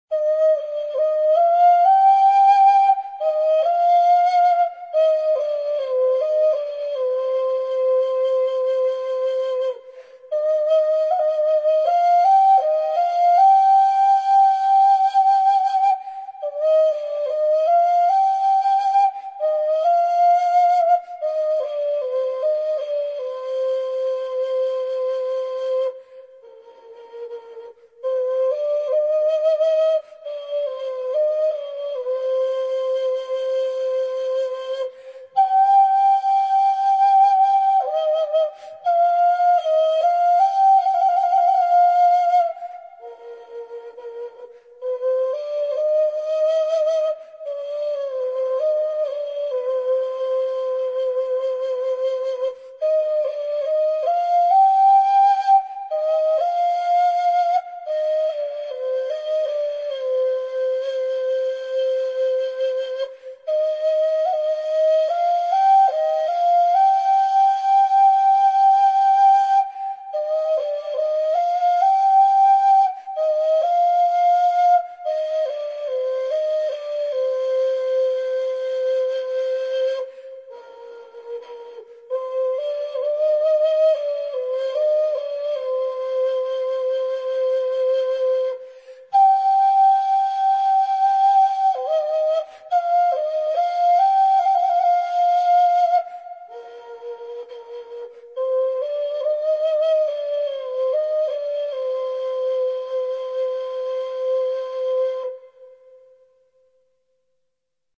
Инструментальные пьесы [9]
Переложение для узкирика.
Ускирик